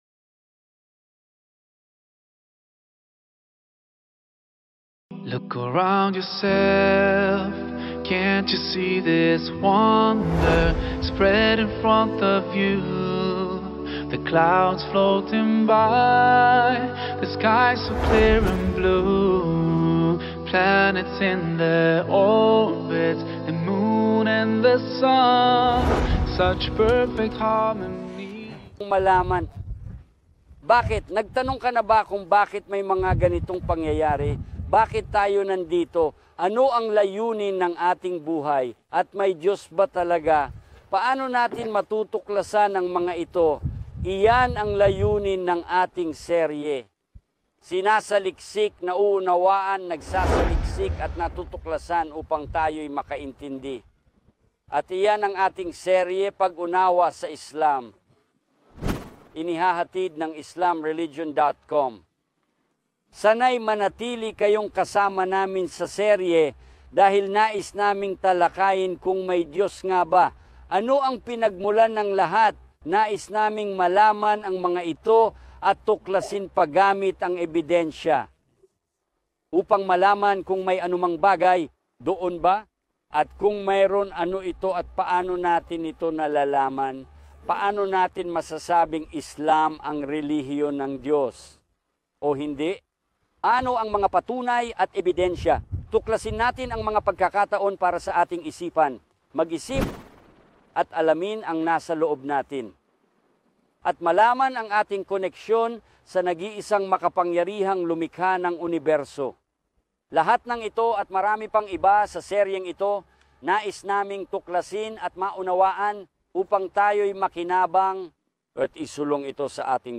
kinunan sa backdrop ng mga magagandang tanawin at makasaysayang lugar ng Jordan. Sa episode na ito, ipinakikilala niya ang serye.